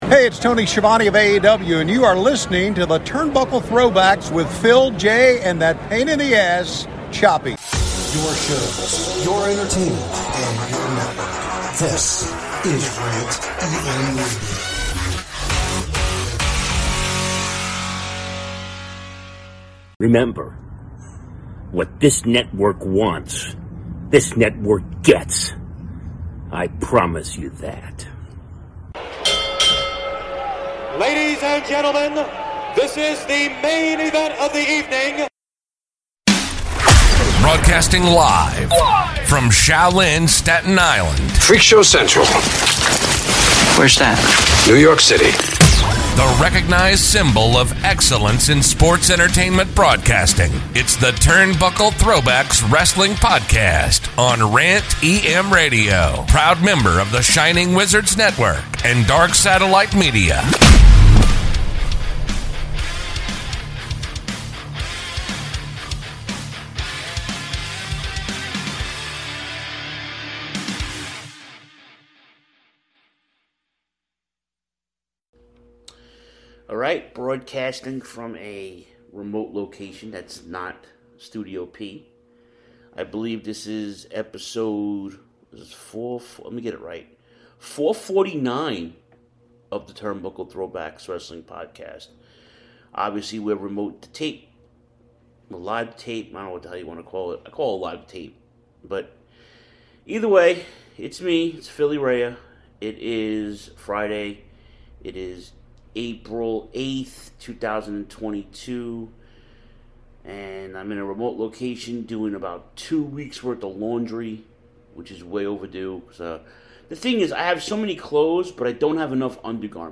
solo this week from a remote location. Reviewing all the comings and goings from Mania weekend. All of this weeks weekly programming including Cody’s return to RAW, Bobby Lashley’s awful face turn, Hangman Page and Adam Cole, yet ANOTHER Hardy’s table match and Sunny in trouble again.